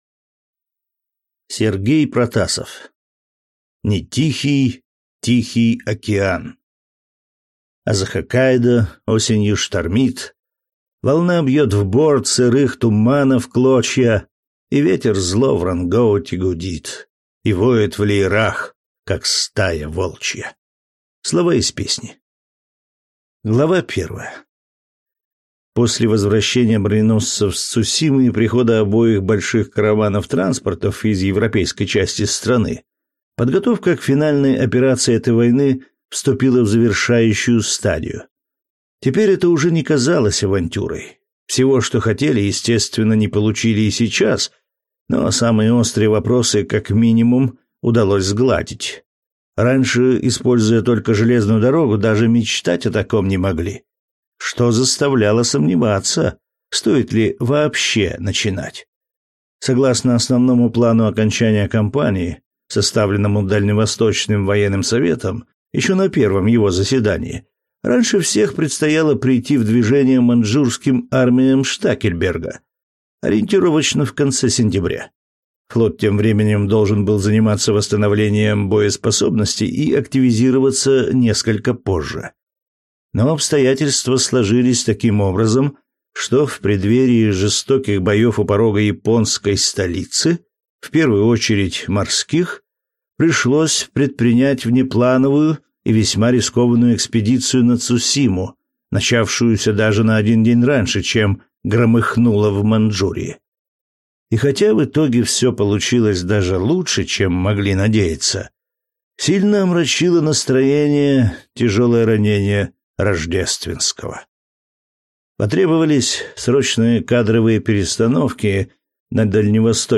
Аудиокнига Цусимские хроники. Не тихий Тихий океан | Библиотека аудиокниг